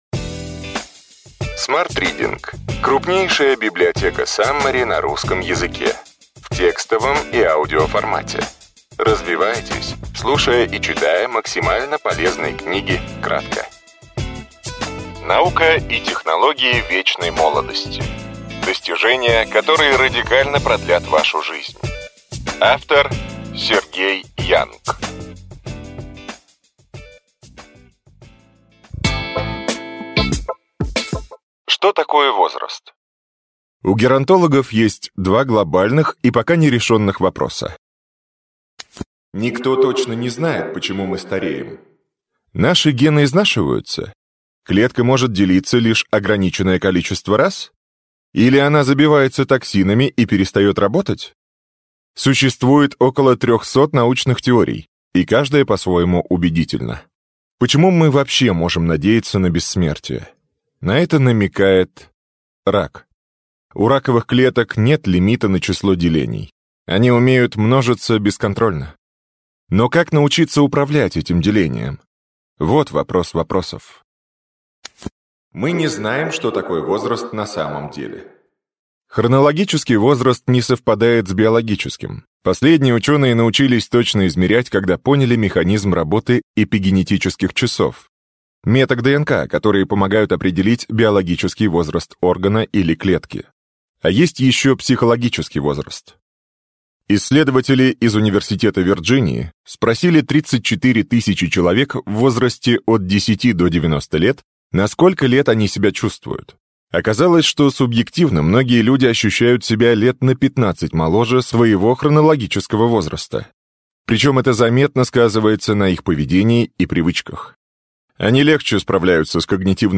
Аудиокнига Ключевые идеи книги: Наука и технологии вечной молодости. Достижения, которые радикально продлят вашу жизнь.